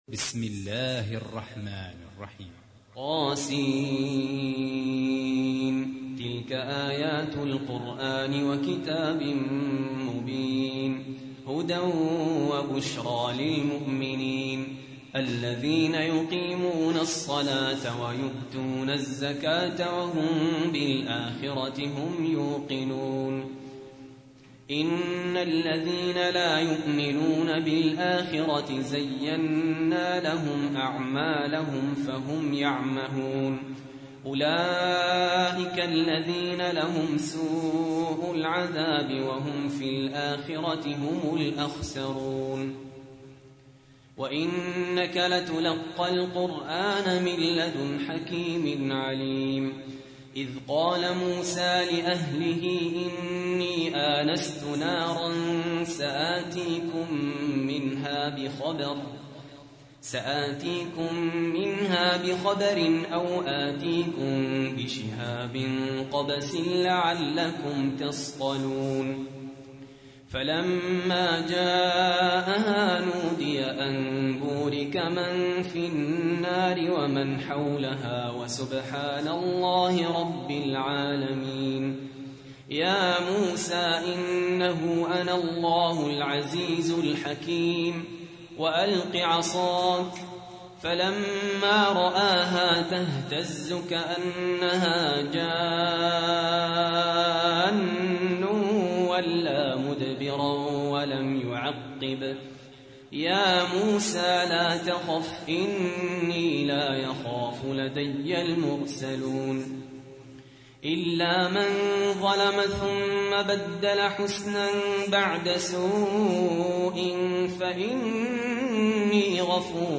تحميل : 27. سورة النمل / القارئ سهل ياسين / القرآن الكريم / موقع يا حسين